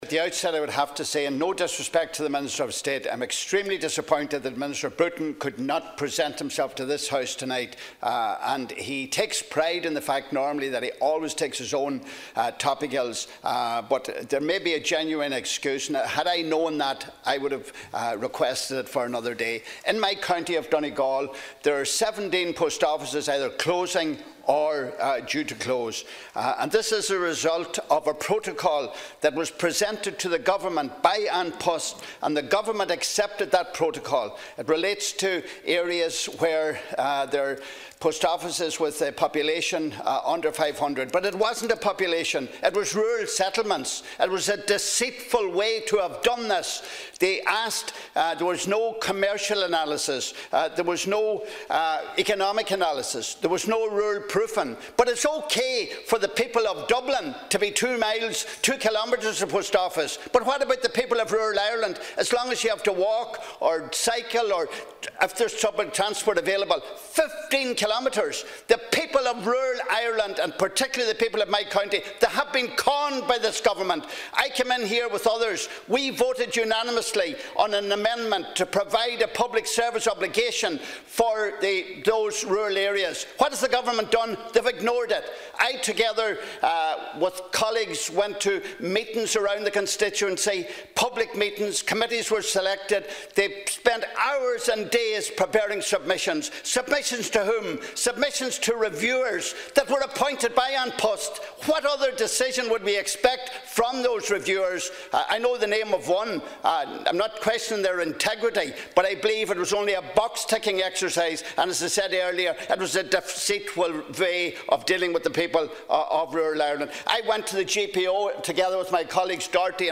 A Donegal Deputy has told the Dail that the people of rural Ireland have been conned by the Government. Speaking in the Dail last evening during topical issues on the closure of Post Offices in Donegal and Tipperary, Deputy Pat the Cope Gallagher told Minister Sean Canney that the protocol from An Post accepted by the Government was a deceitful act and has lead to 17 Post Offices in Donegal being earmarked for closure.